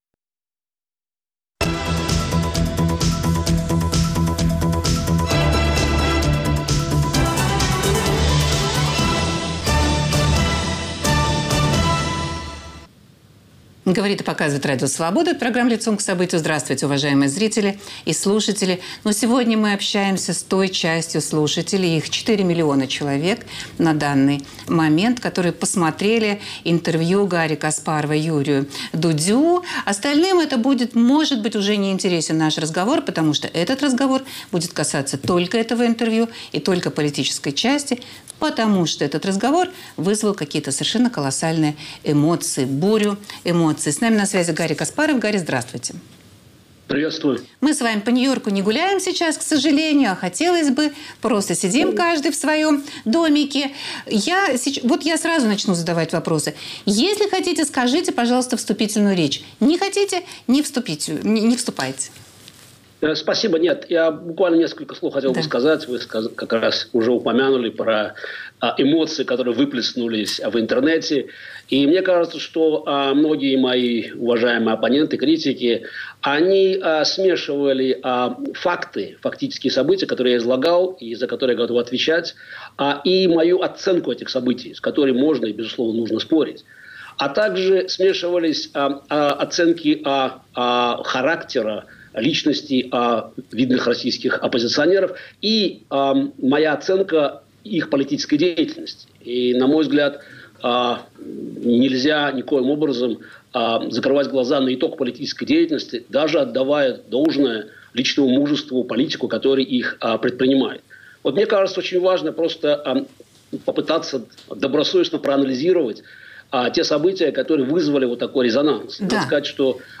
Политик Гарри Каспаров в диалоге с аудиторией РС по мотивам своего интервью Юрию Дудю.